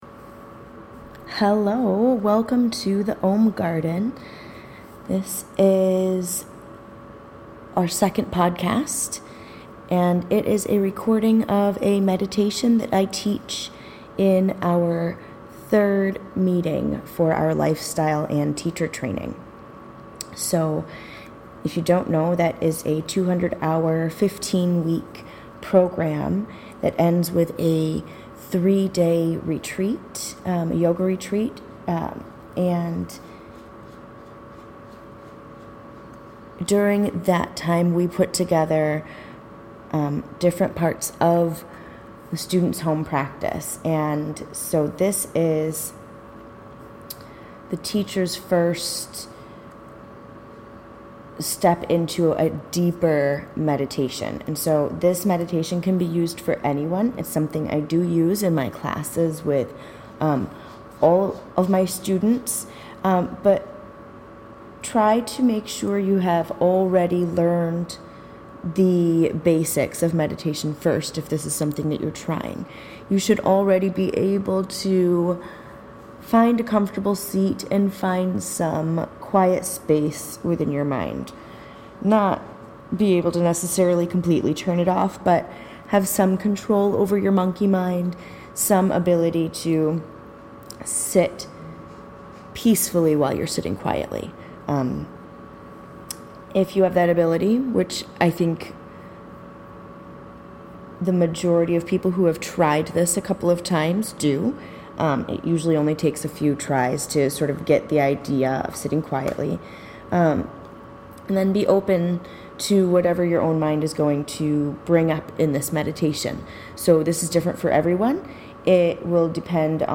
This guided meditation is an excerpt
during a live training